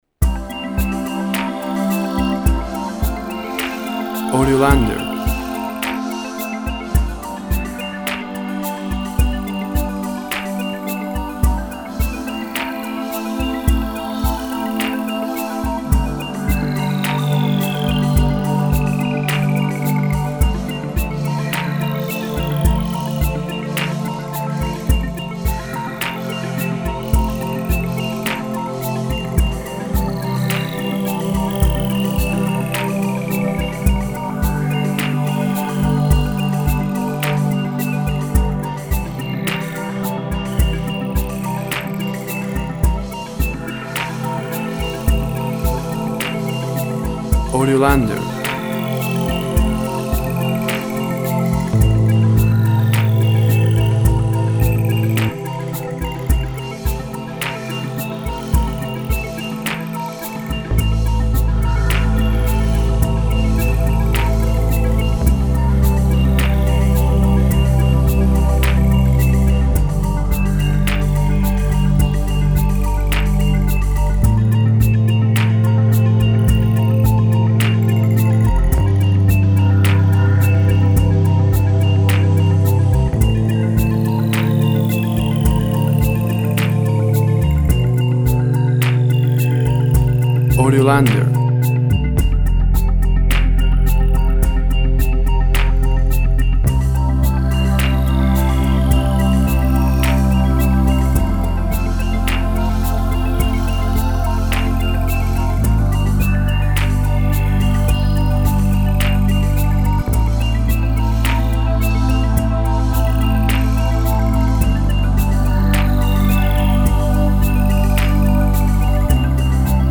Music Relaxed, with electronic sounds.
Tempo (BPM) 53